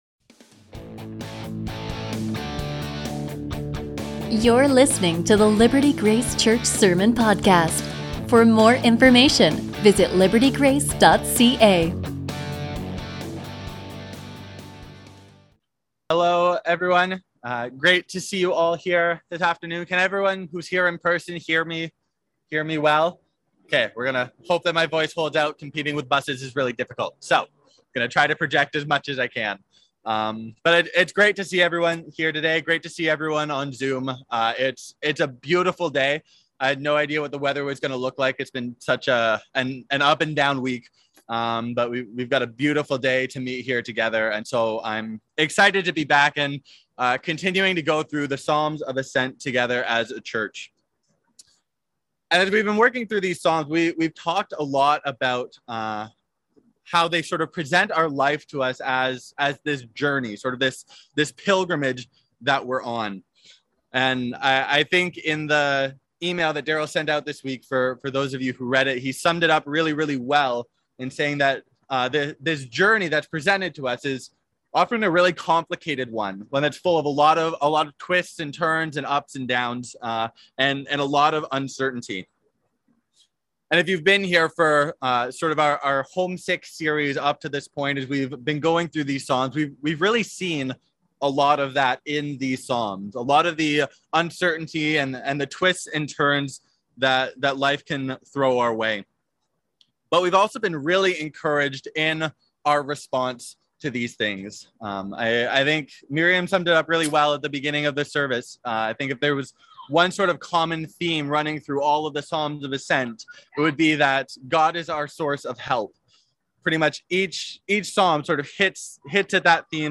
A message from the series "Homesick."